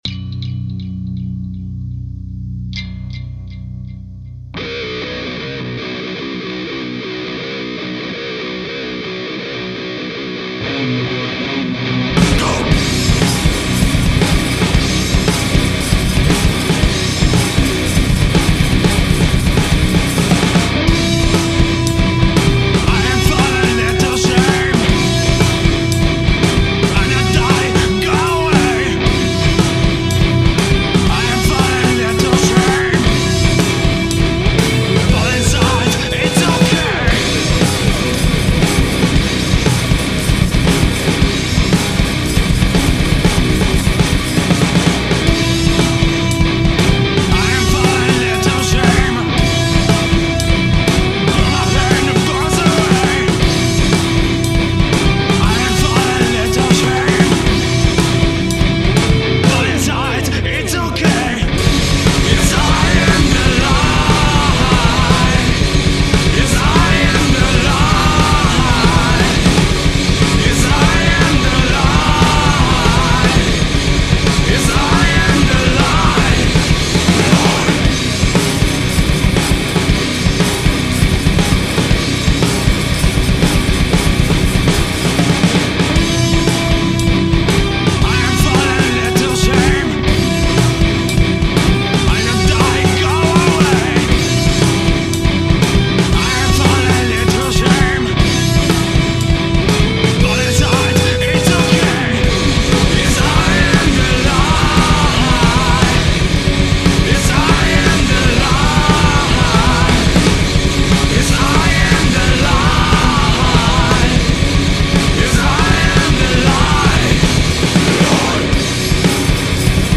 Gatunek: nu metal/thrash/hardcore/metal